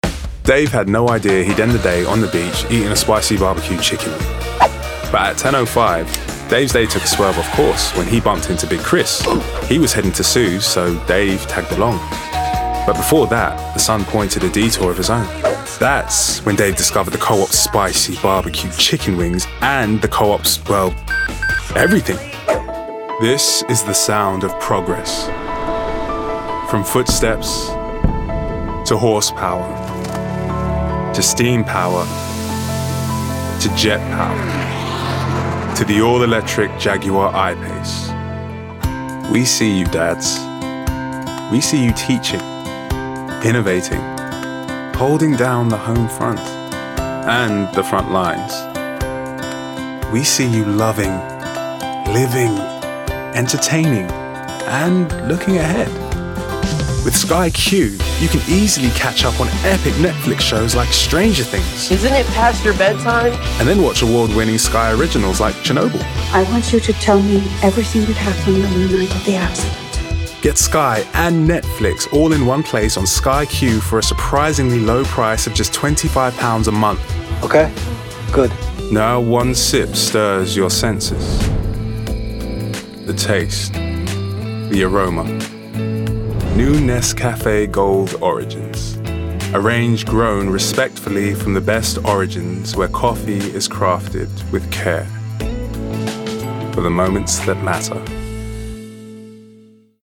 Description: London: effortless, gravitas, moving
Age range: 20s - 30s
Commercial 0:00 / 0:00
London*